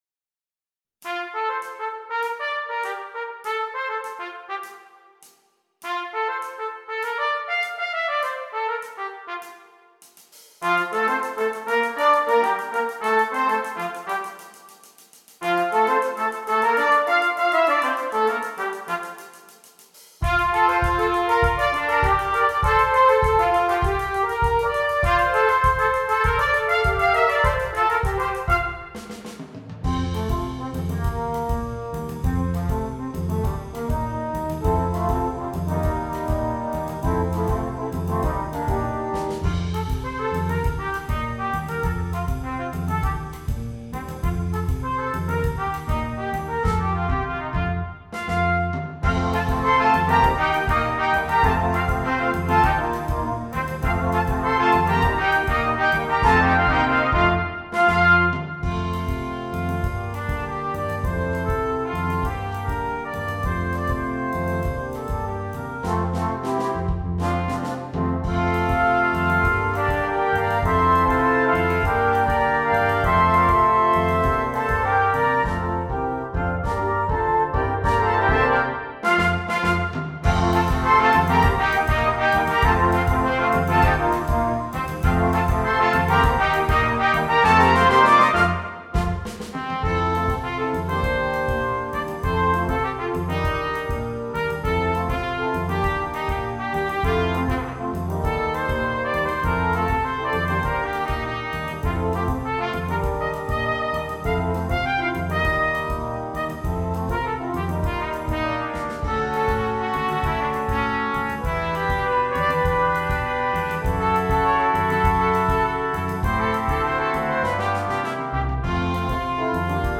8 Trumpets